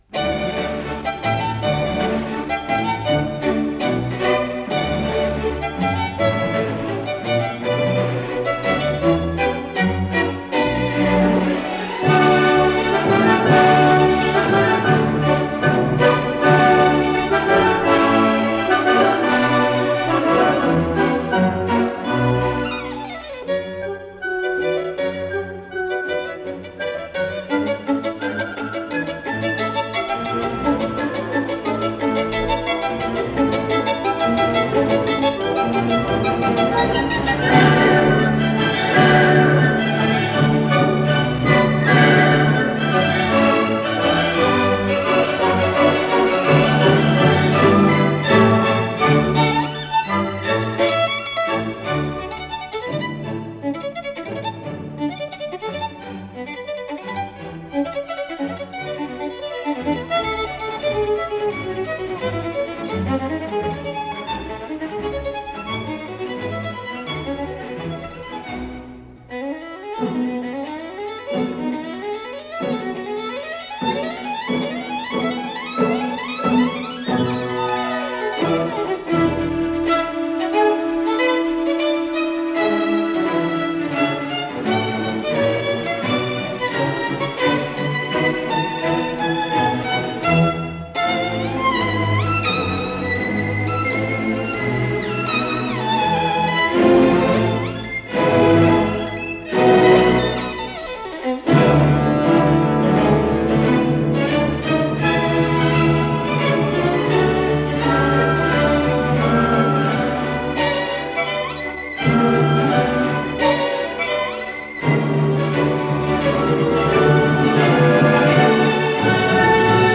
с профессиональной магнитной ленты
ИсполнителиДавид Ойстрах - скрипка
АккомпаниментКливлендский симфонический оркестр
Дирижёр - Джордж Сэлл
ВариантДубль моно